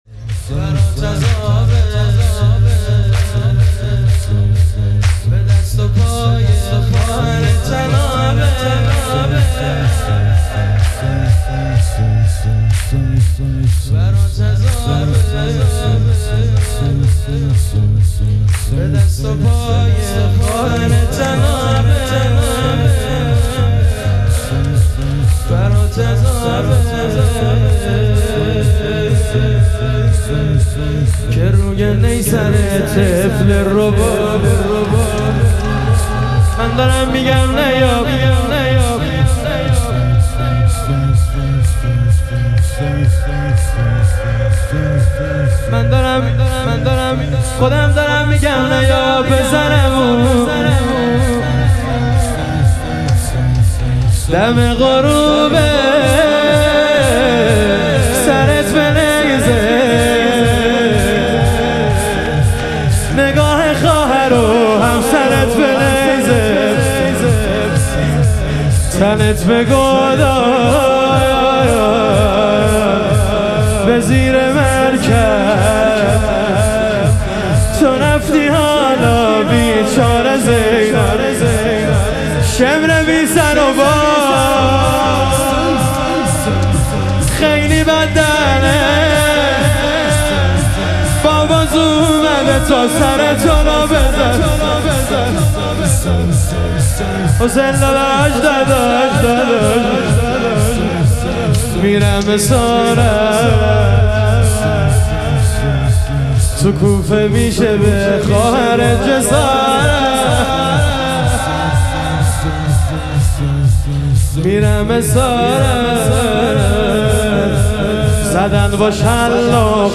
لطمه زنی
شب شهادت حضرت مسلم علیه السلام